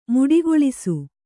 ♪ muḍigoḷisu